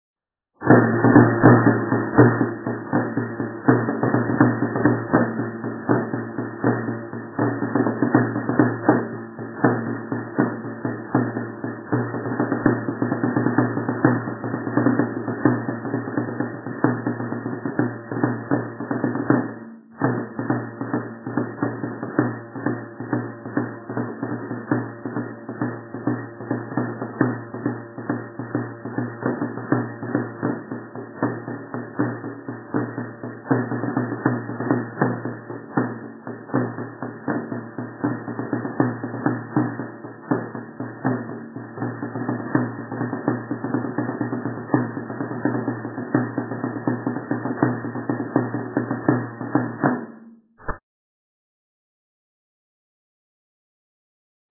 09)  Toque de jota (cuando no toca la donzaina).